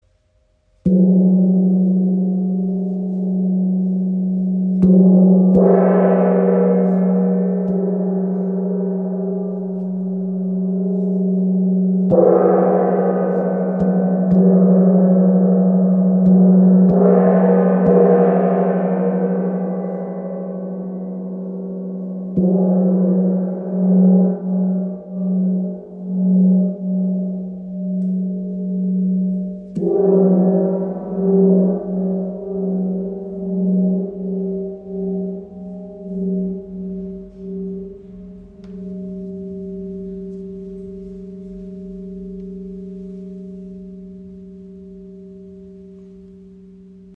Feng Gong Ø 50 cm im Raven-Spirit WebShop • Raven Spirit
Klangbeispiel
Dadurch können sich die Schwingungen leichter ausbreiten und der Gong kann durch Anschlagen in kräftige Vibration gebracht werden. Sein Klang kommt schnell ins Rauschen, weshalb er auch als Wind-Gong bezeichnet wird.